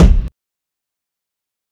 Waka Kick - 1 (3).wav